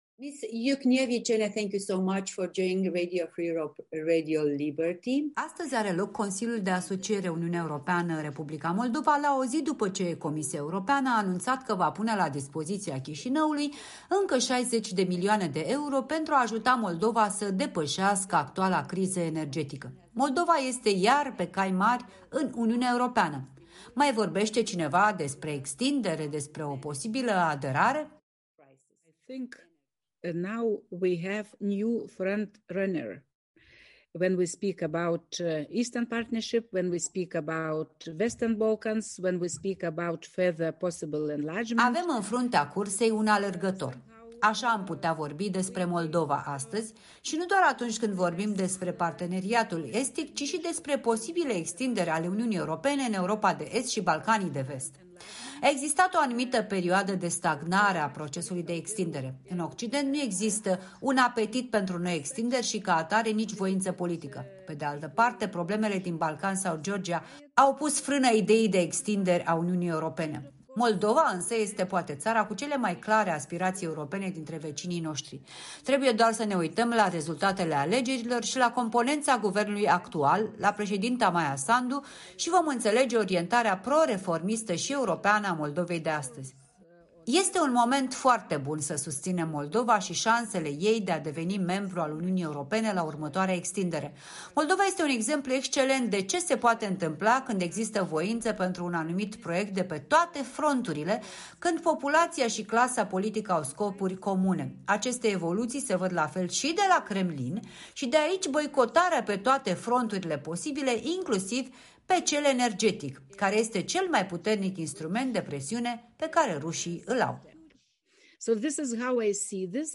Interviu cu europarlamentara lituaniană Rasa Juknevičienė (PPE), vicepreședinte al Subcomisiei de securitate și apărare din Parlamentul European.